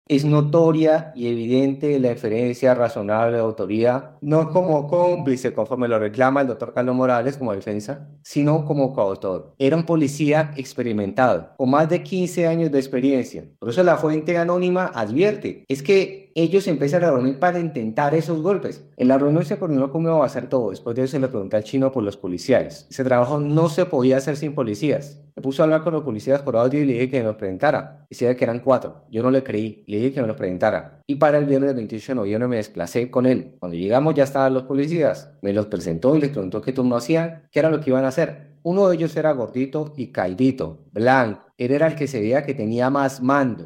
Testimonio audiencia medida de aseguramiento hurto a joyería